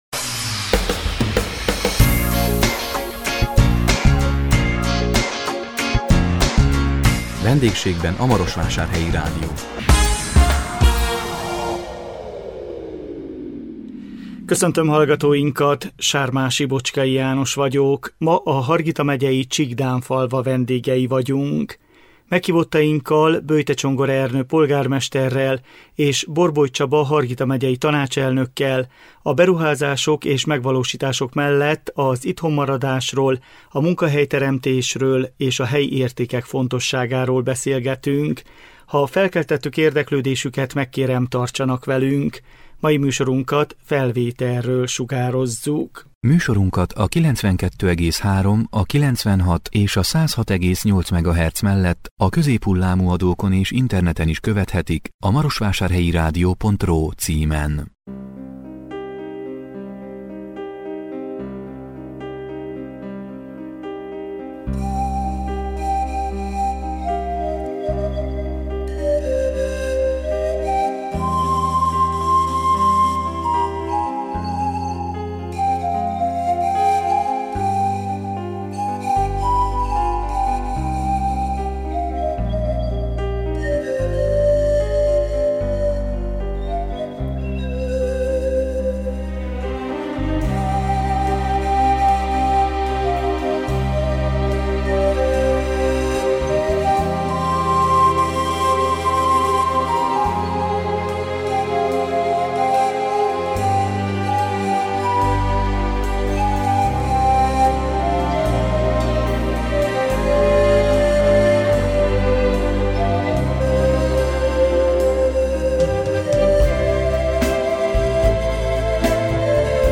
A 2021 augusztus 19-én jelentkező VENDÉGSÉGBEN A MAROSVÁSÁRHELYI RÁDIÓ című műsorunkban a Hargita megyei Csíkdánfalva vendégei voltunk. Meghívottainkkal, Bőjte Csongor Ernő polgármesterrel és Borboly Csaba Hargita megyei tanácselnökkel a beruházások és megvalósítások mellett az itthon maradásról, a munkahelyteremtésről és a helyi értékek fontosságáról beszélgettünk.